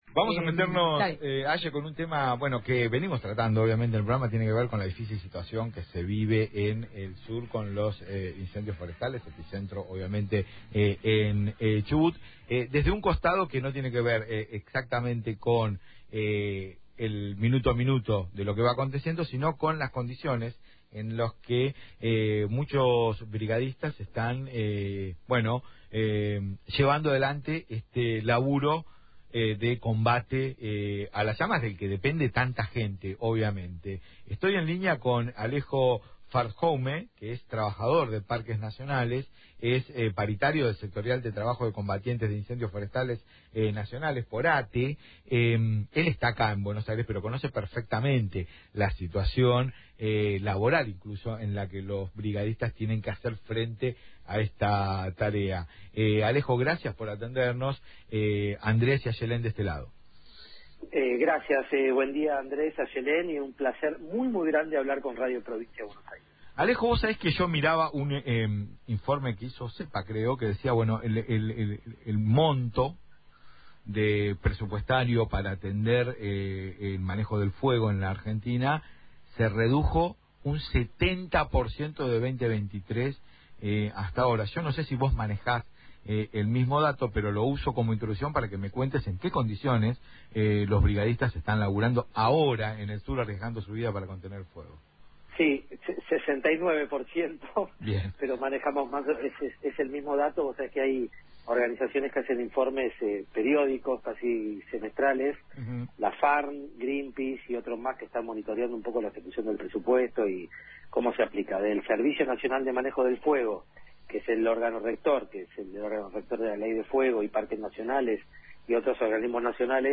En contacto con “Es un montón”, por Radio Provincia 1270, dio detalles sobre la situación laboral de brigadistas, las condiciones de trabajo, salarial y contexto que tienen que enfrentar en la tarea de sofocar incendios en zonas de riesgo alto.